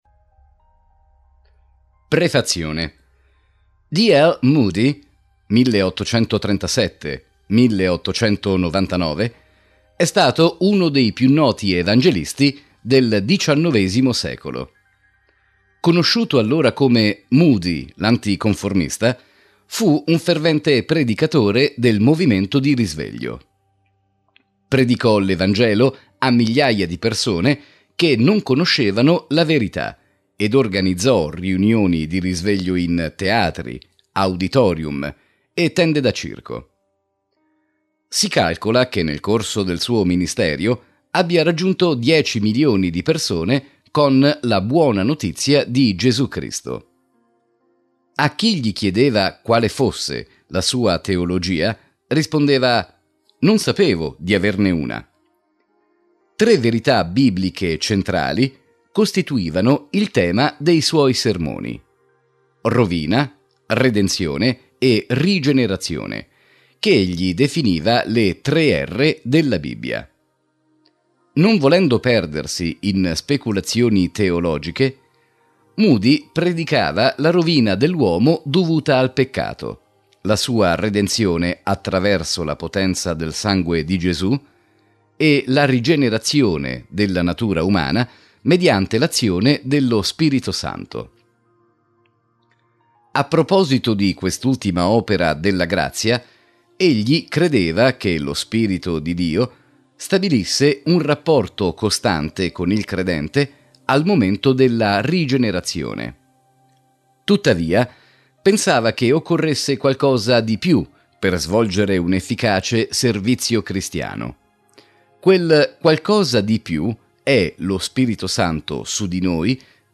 3. Audiolibri
Lettura integrale MP3